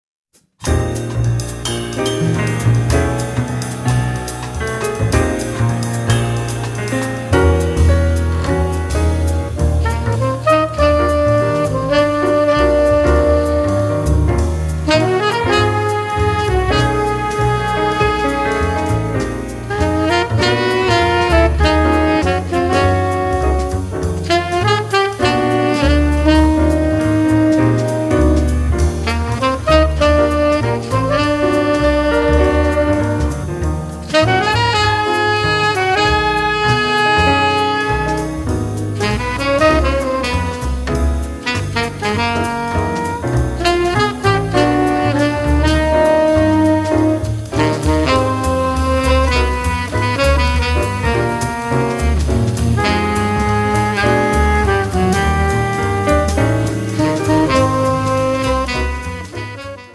sax tenore e soprano
pianoforte
contrabbasso
batteria